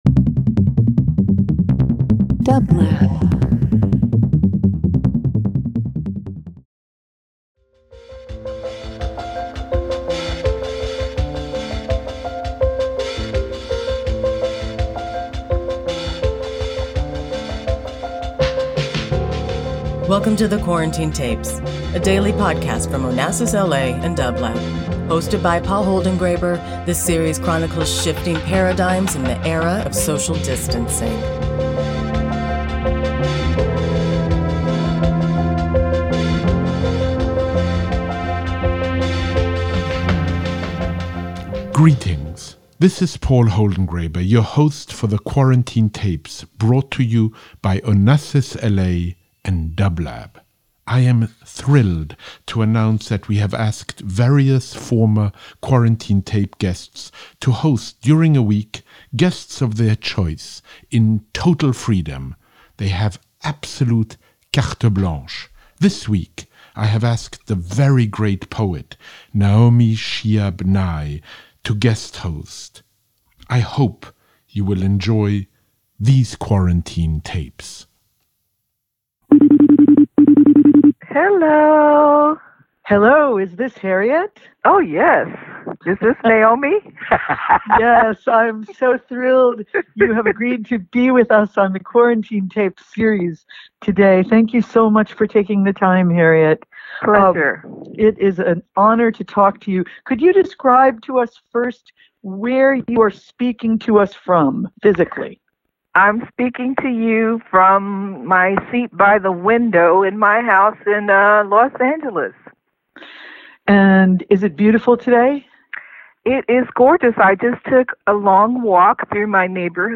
Each day, Paul calls a guest for a brief discussion about how they are experiencing the global pandemic.
About episode 142: Guest host Naomi Shihab Nye is joined by poet Harryette Mullen on episode 142 of The Quarantine Tapes. Harryette reflects on her habit of walking in Los Angeles and how that has served her well during the isolation of quarantine.
In this reflective, heartfelt conversation, Naomi celebrates Harryette’s work, lifting up her use of language and inviting Harryette to read from her poems.